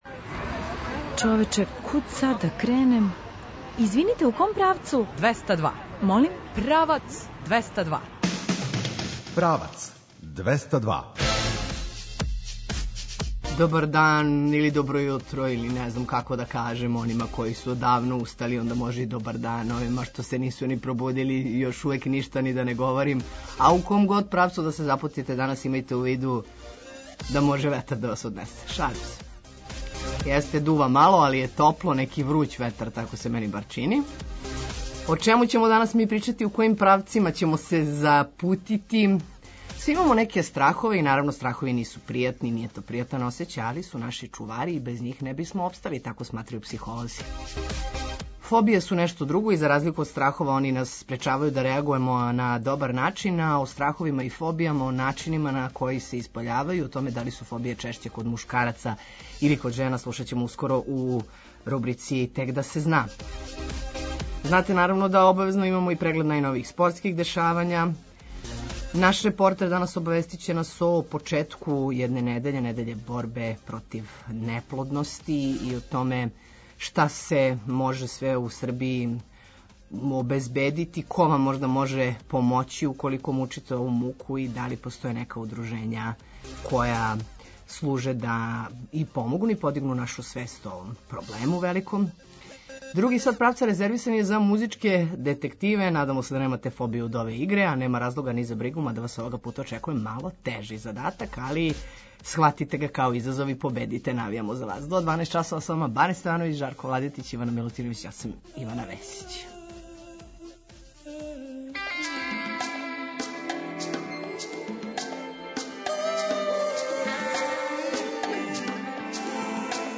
Други сат Правца резервисан је за музичке детективе.